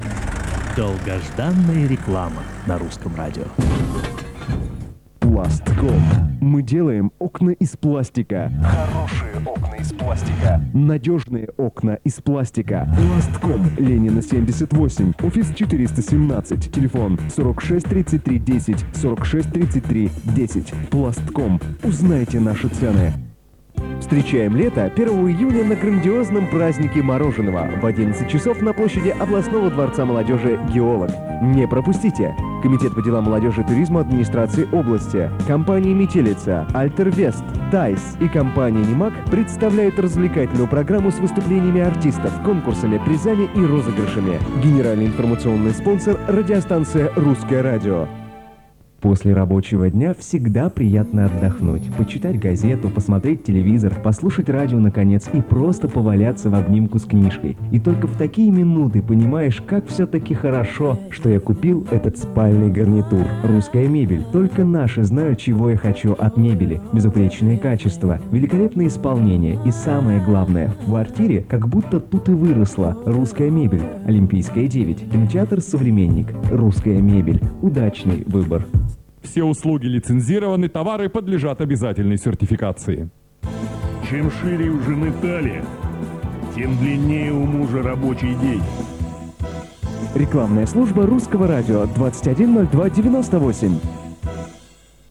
Местная реклама (Русское радио (Тюмень), ~05.2002) Пластком, Праздник мороженого, Русская мебель